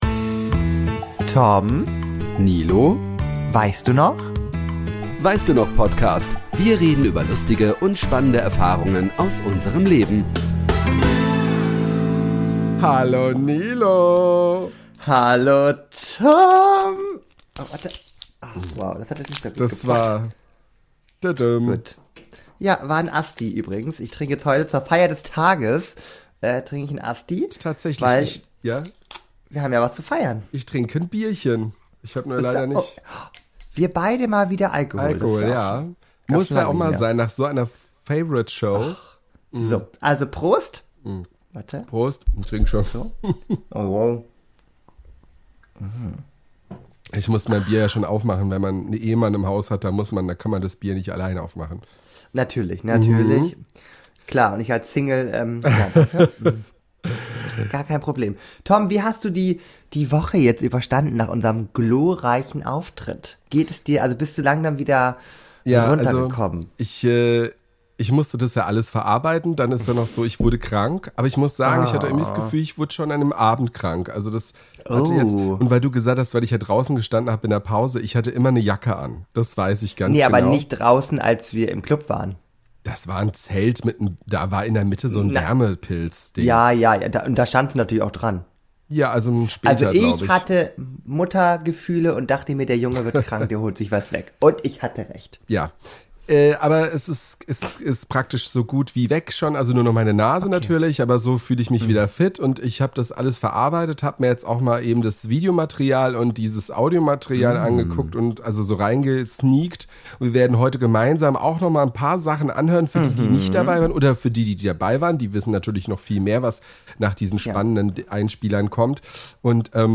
Freut euch auf exklusive Einblicke, ehrliche Analysen und kommentierte Highlights direkt aus dem Live-Programm.